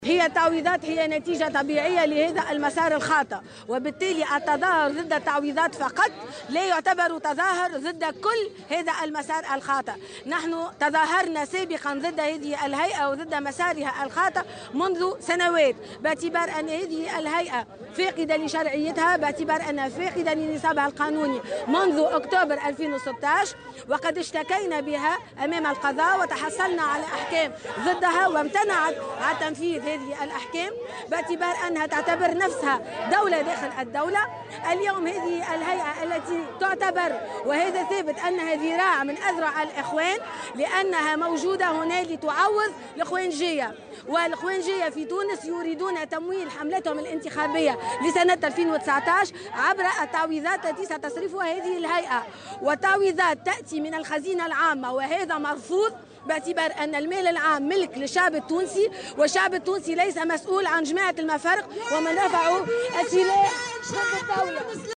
نفذ اليوم الخميس أنصار الحزب الدستوري الحر وقفة احتجاجية أمام مقر صندوق التقاعد والحيطة الاجتماعية للمحامين بتونس ضد هيئة الحقيقة والكرامة.